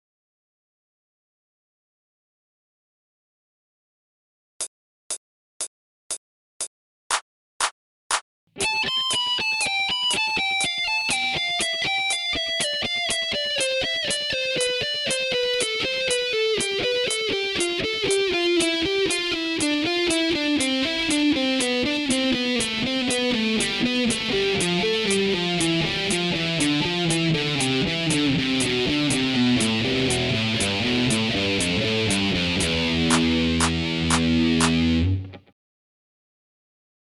training phrase 1　メジャースケール〜下降フレーズ〜
１弦２０フレットから６弦０フレットまでの下降フレーズです。
４音ずつのよくある下降フレーズですが、あまり４音ずつ区切った意識は持たないほうが良いでしょう。